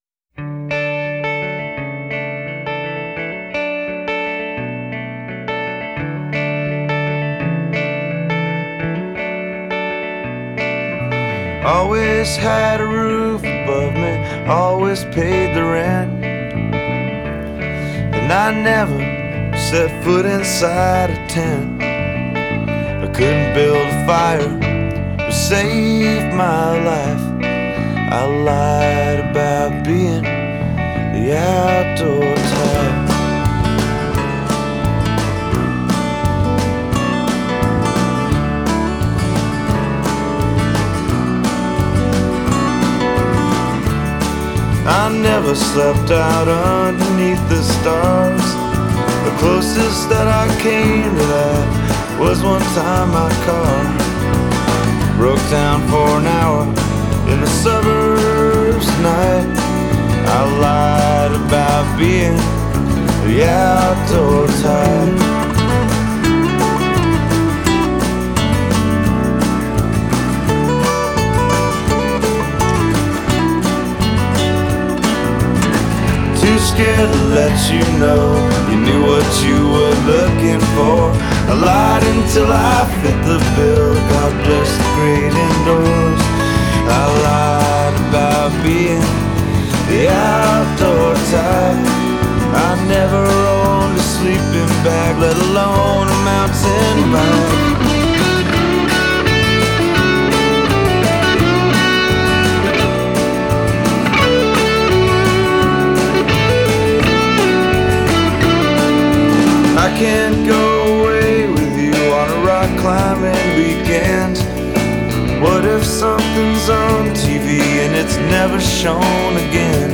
warm voice
simple, accessible, and slightly subversive songwriting